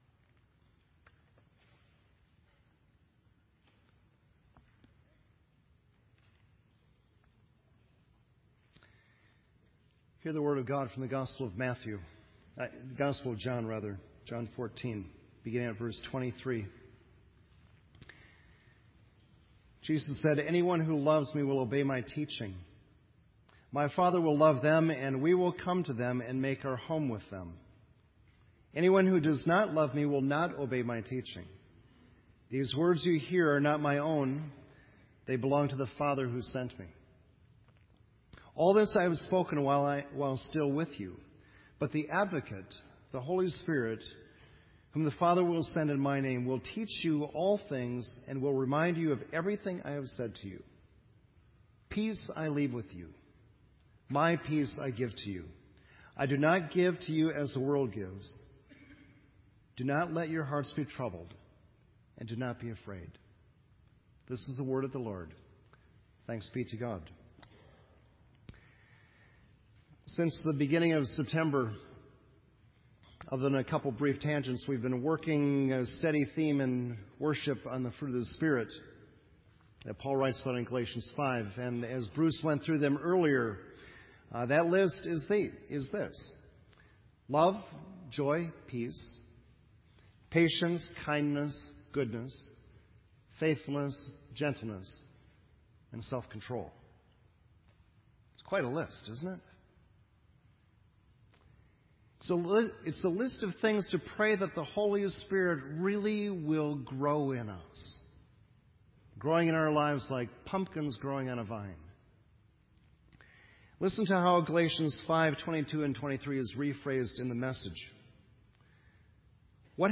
This entry was posted in Sermon Audio on November 24